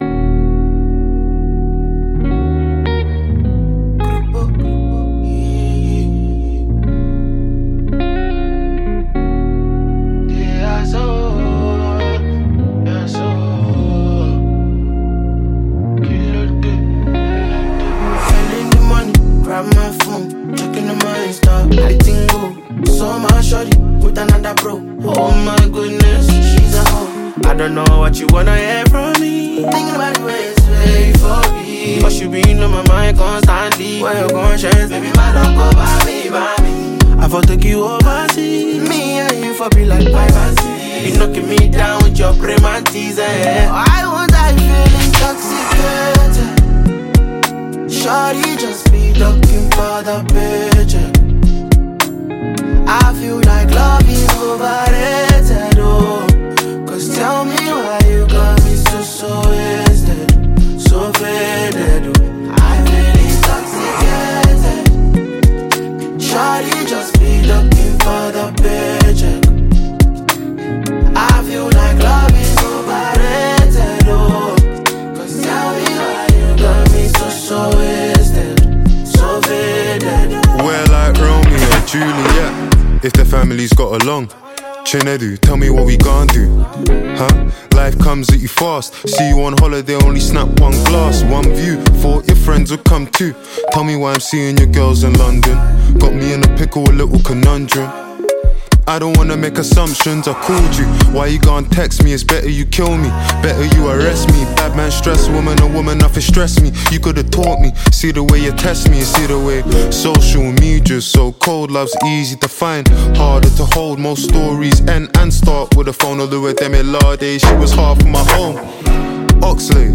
is a deep, melodic, and intriguing song